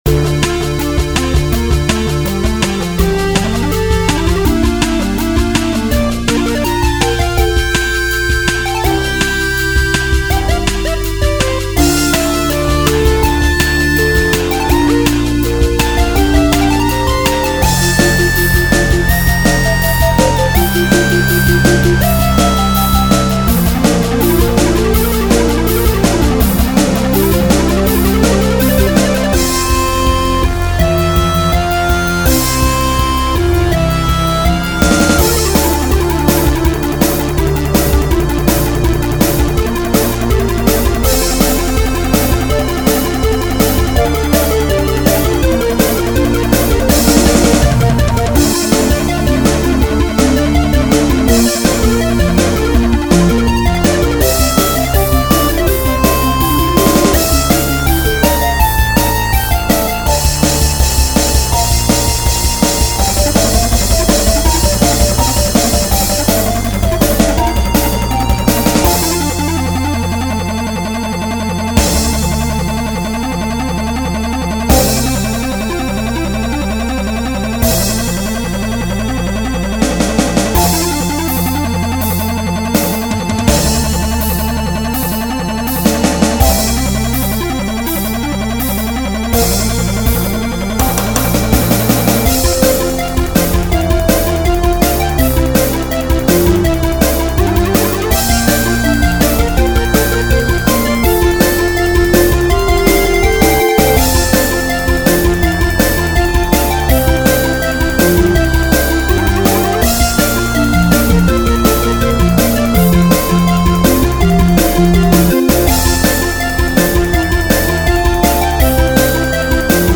This song is a silly mix of pop and electronica.
ELECTRONICA MUSIC